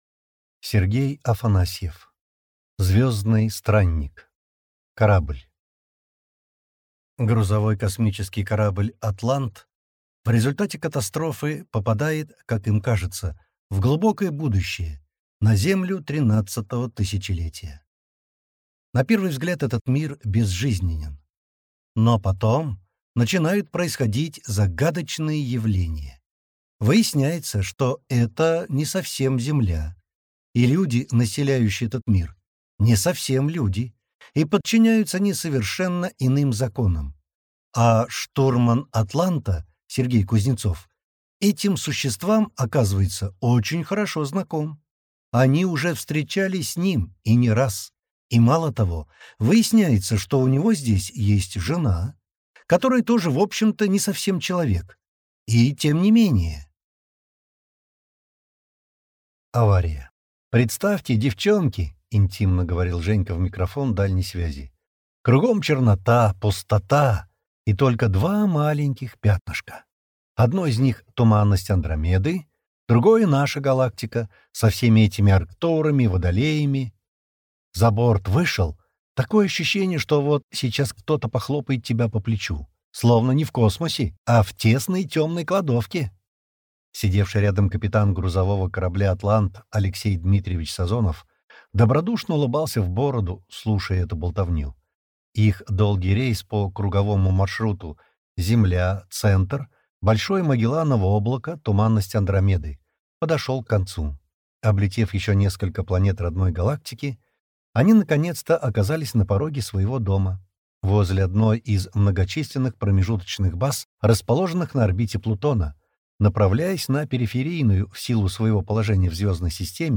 Аудиокнига Звездный странник. Корабль | Библиотека аудиокниг